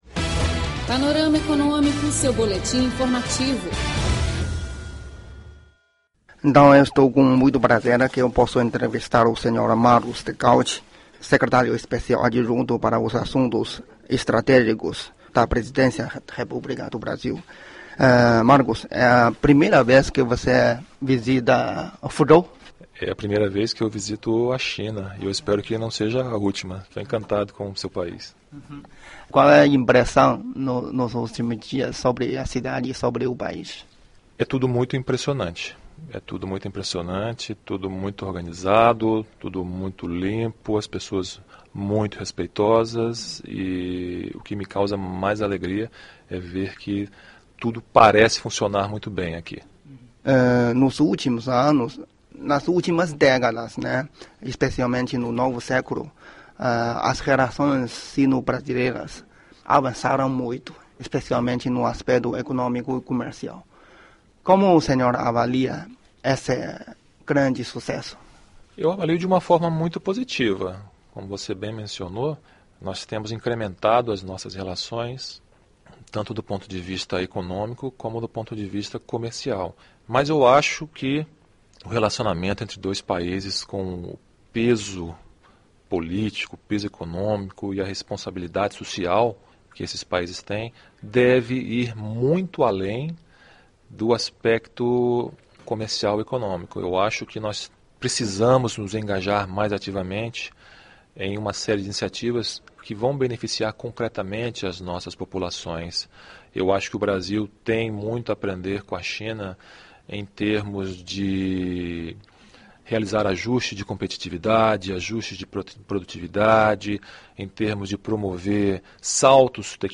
Entrevista com Marcos Degaut, secretário especial adjunto de Assuntos Estratégicos da Presidência do Brasil - china radio international
economia0710 Hoje, teremos a entrevista com Marcos Degaut, secretário especial adjunto de Assuntos Estratégicos da Presidência da República Federativa do Brasil.
no Fórum dos Partidos, Think-tanks e Organizações Sociais do Brics, realizado em junho, em Fuzhou, capital da província de Fujian, sudeste da China.